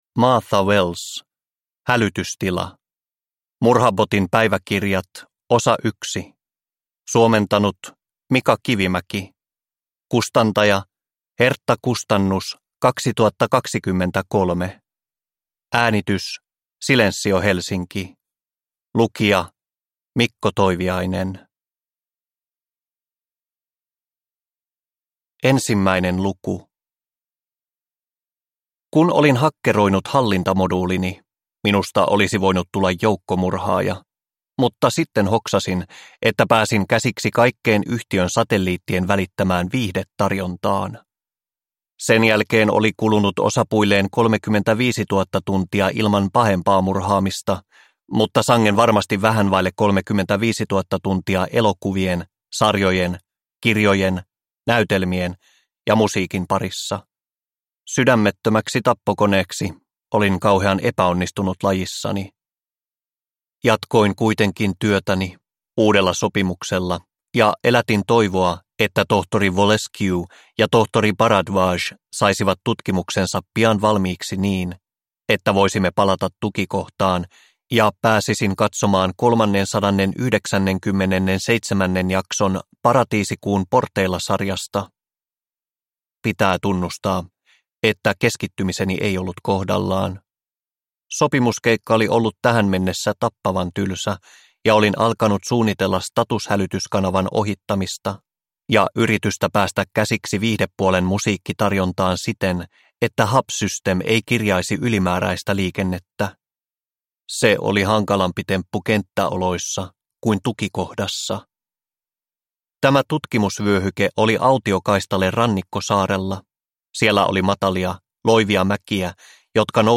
Hälytystila – Ljudbok – Laddas ner